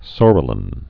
(sôrə-lən)